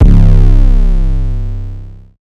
TM88 [808].wav